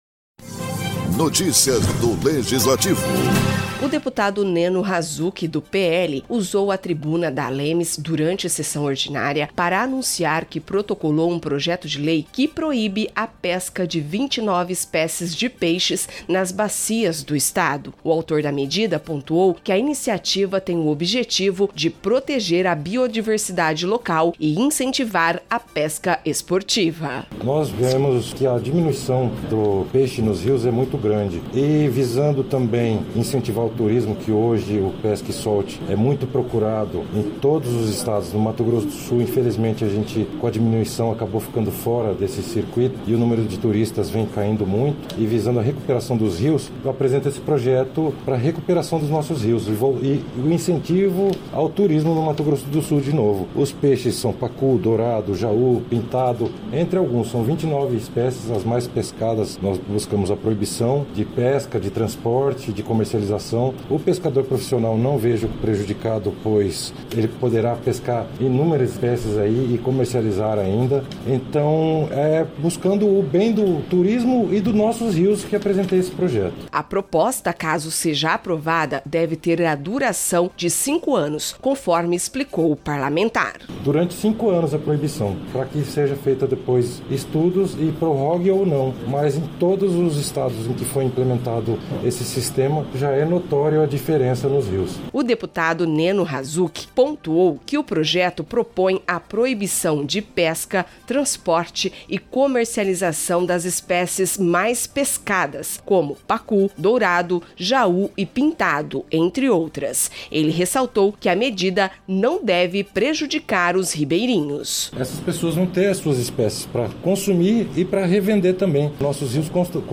O deputado Neno Razuk (PL) usou a tribuna da ALEMS, durante sessão ordinária, para anunciar que protocolou um projeto de lei que proíbe a pesca de 29 espécies de peixes nas bacias do Estado.